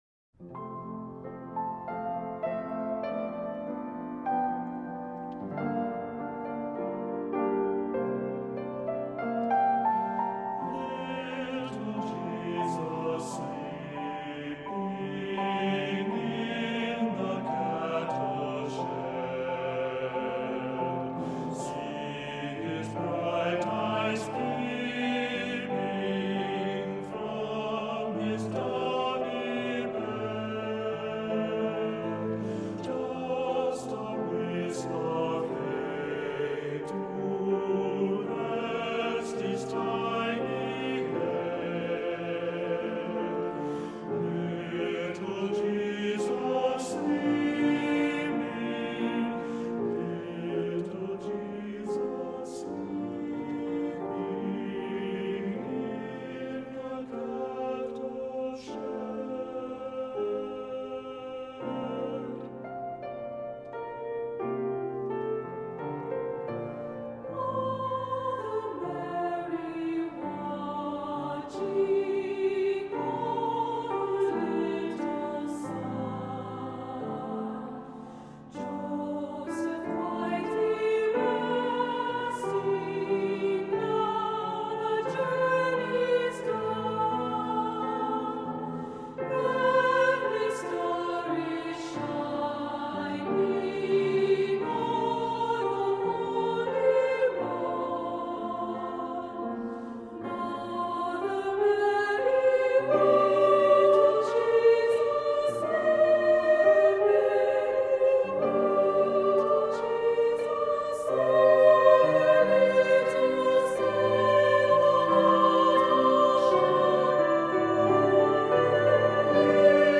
Voicing: Unison; Unison with descant